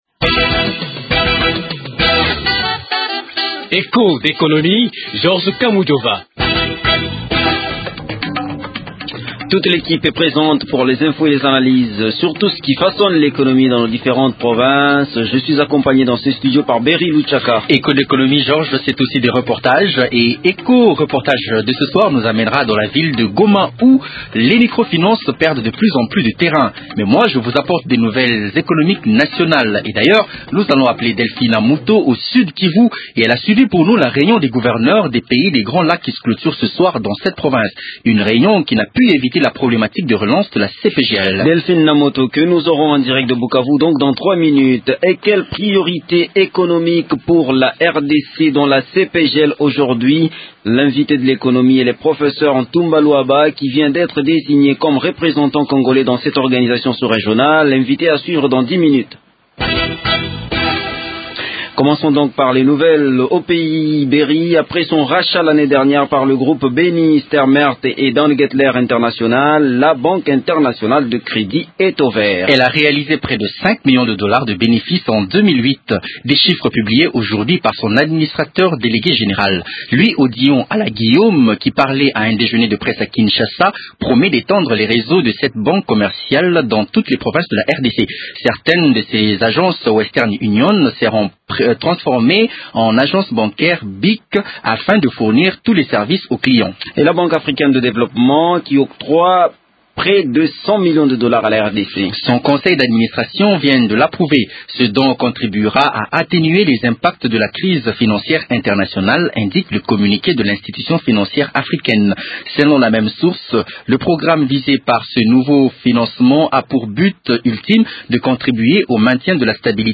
La communauté économique des pays des grands lacs qui a été relancée depuis deux ans, s’affiche comme un cadre de stabilité pour la région. Il est l’invité de l’émission. Echos d’économie fait aussi escale a Goma où les institutions de microfinance commencent à perdre du poids.